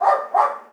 dog_bark_small_01.wav